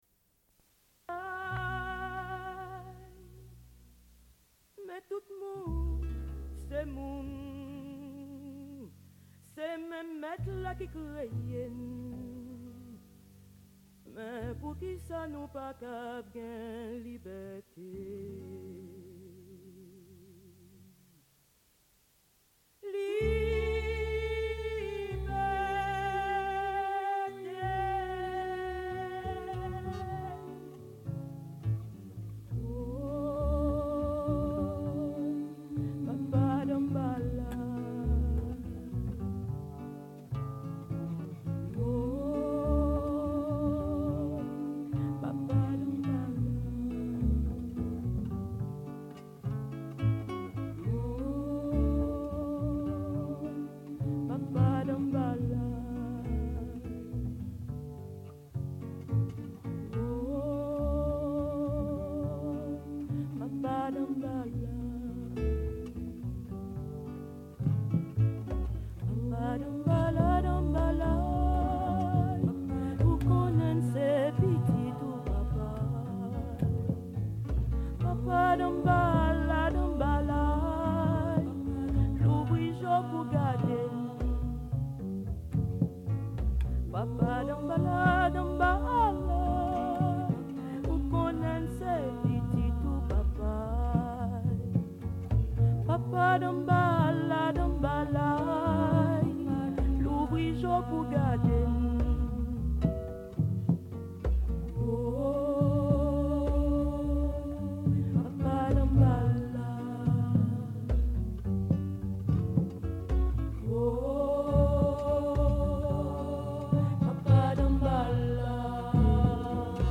Entretien en direct
Une cassette audio, face A